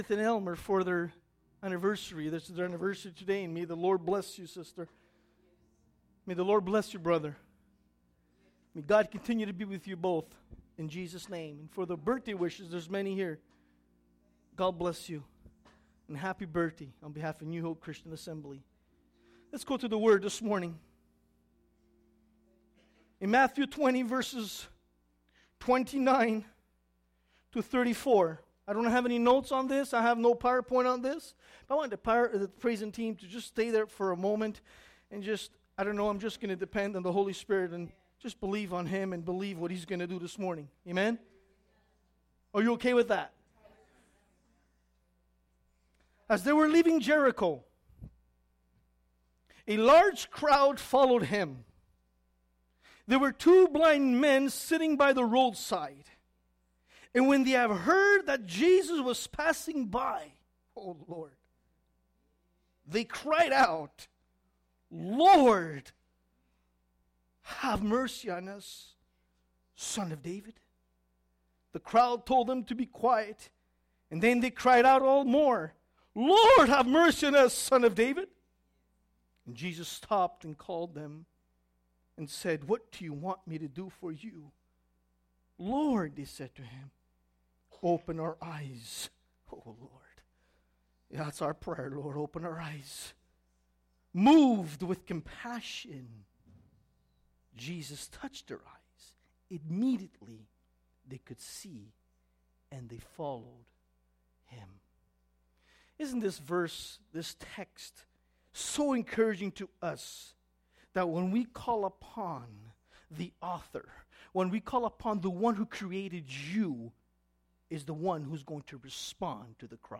Sermons | New Hope Christian Assembly